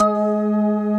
B3PLUCKG#3.wav